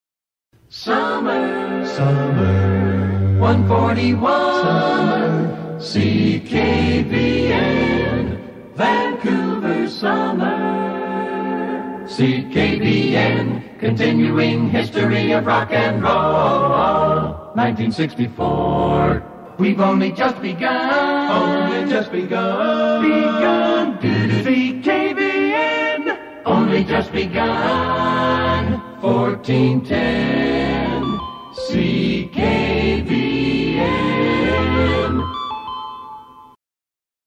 Jingle Montages Courtesy of